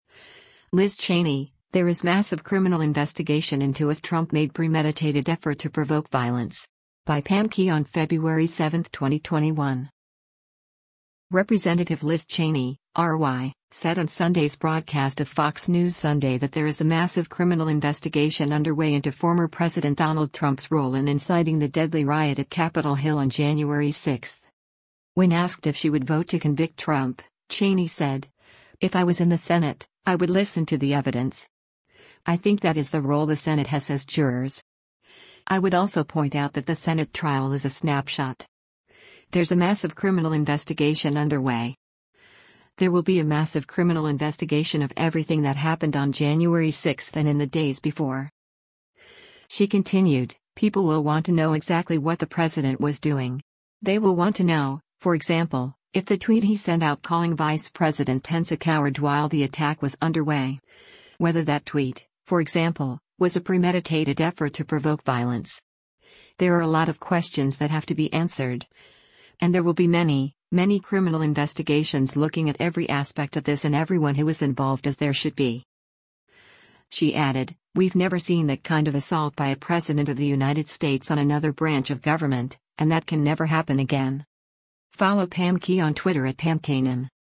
Representative Liz Cheney (R-WY) said on Sunday’s broadcast of “Fox News Sunday” that there is a “massive criminal investigation underway” into former President Donald Trump’s role in inciting the deadly riot at Capitol Hill on January 6.